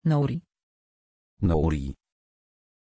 Aussprache von Nouri
pronunciation-nouri-pl.mp3